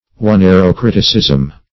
Search Result for " oneirocriticism" : The Collaborative International Dictionary of English v.0.48: Oneirocriticism \O*nei`ro*crit`i*cism\, Oneirocritics \O*nei`ro*crit`ics\, n. The art of interpreting dreams.